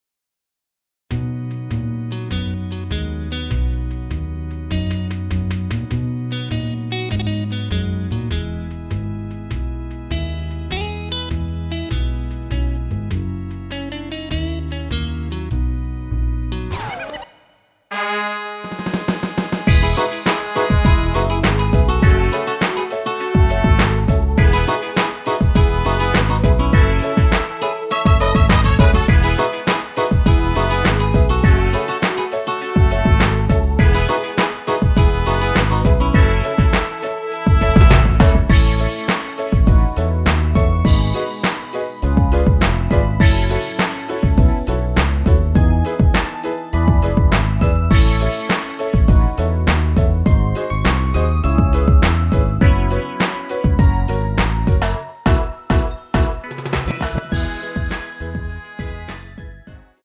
원키에서(-2)내린 랩부분 삭제한 MR입니다.(미리듣기및 가사참조)
◈ 곡명 옆 (-1)은 반음 내림, (+1)은 반음 올림 입니다.
앞부분30초, 뒷부분30초씩 편집해서 올려 드리고 있습니다.